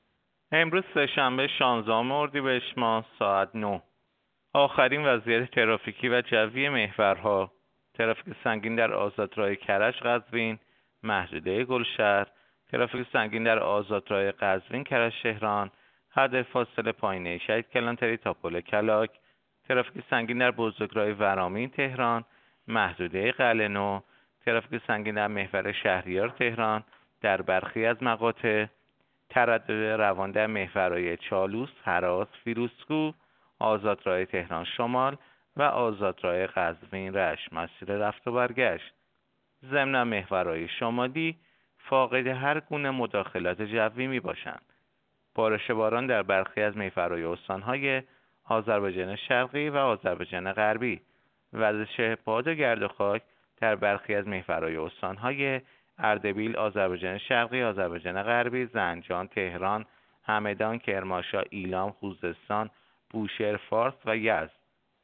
گزارش رادیو اینترنتی از آخرین وضعیت ترافیکی جاده‌ها ساعت ۹ شانزدهم اردیبهشت؛